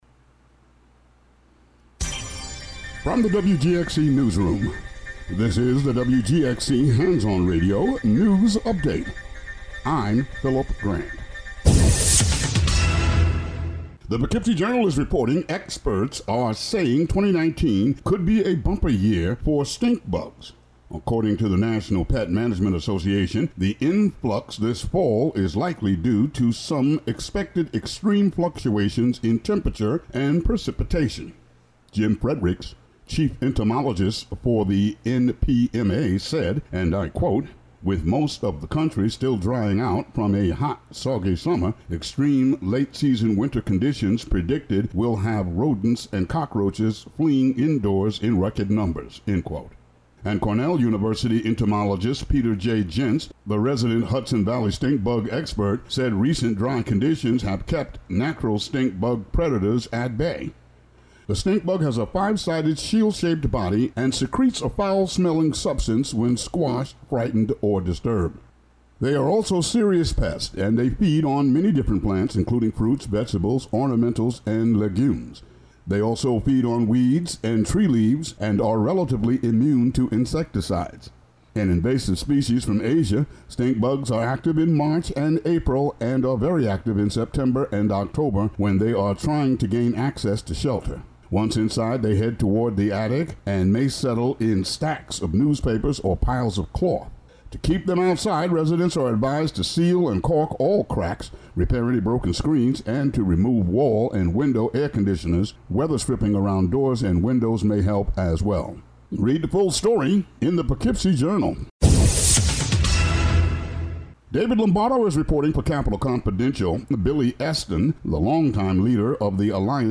Local daily news update.